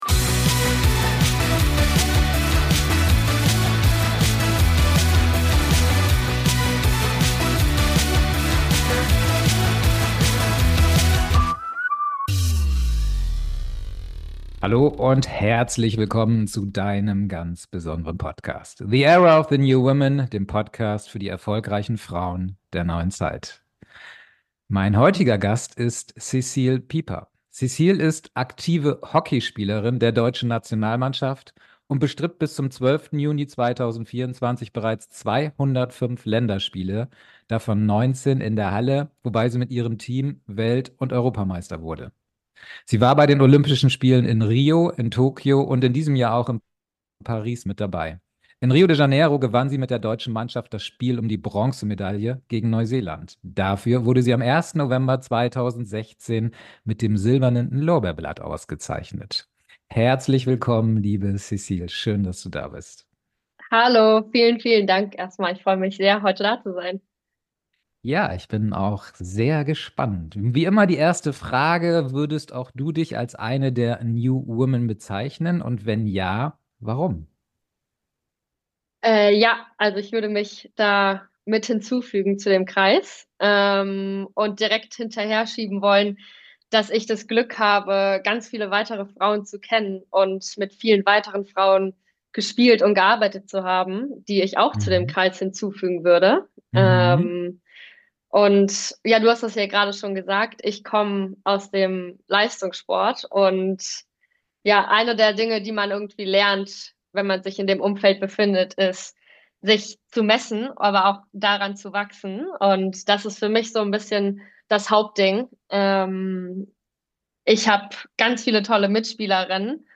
#036 Echte Weiblichkeit im Hochleistungssport. Das Interview mit Olympionikin Cécile Pieper ~ The Era of the New Women Podcast